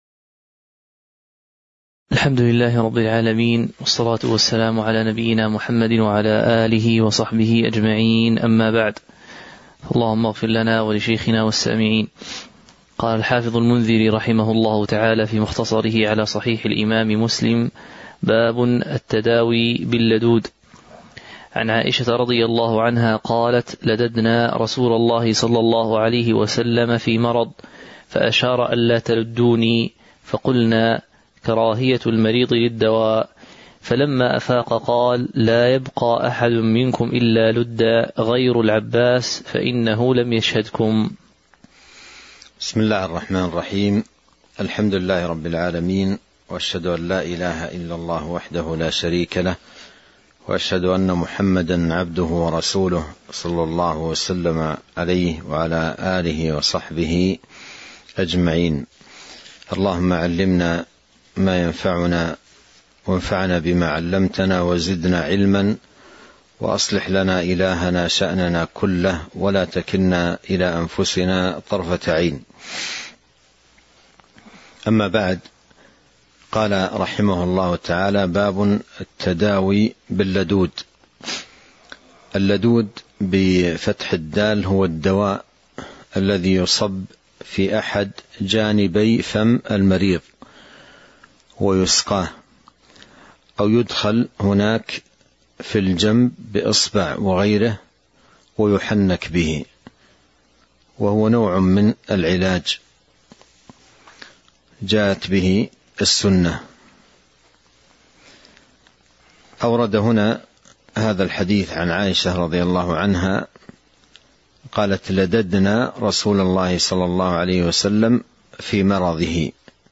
تاريخ النشر ٢٣ رجب ١٤٤٣ هـ المكان: المسجد النبوي الشيخ